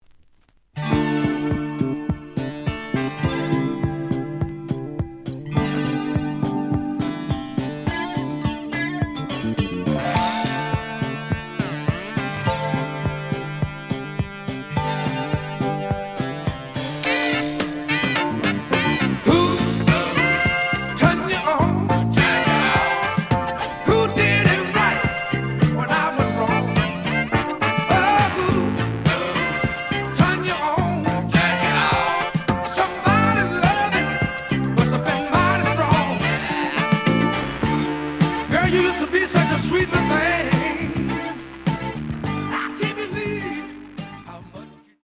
試聴は実際のレコードから録音しています。
●Genre: Disco
EX-, VG+ → 傷、ノイズが多少あるが、おおむね良い。